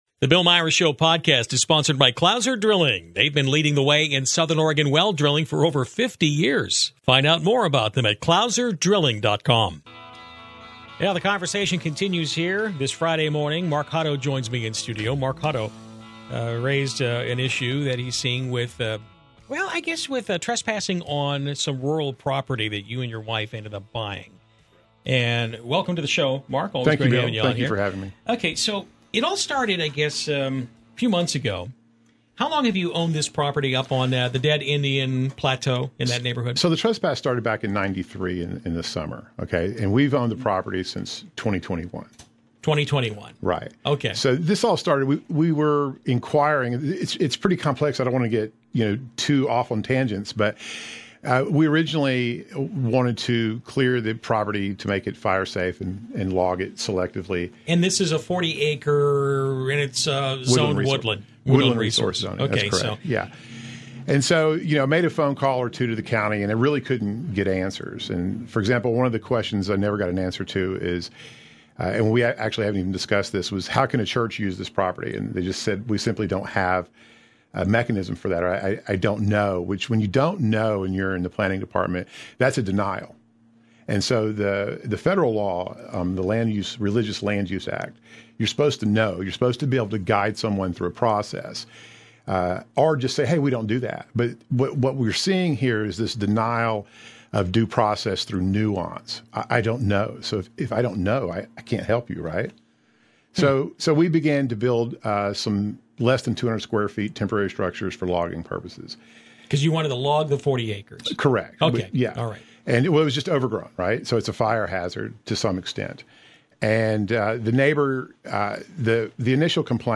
LIVE Local Talk on southern Oregon's KMED FM 106.3, 106.3 HD1, 106.7 FM in S. Jackson County and 99.3 KCMD in Grants Pass and Josephine County.
Morning News